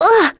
Talisha-Vox_Damage_01.wav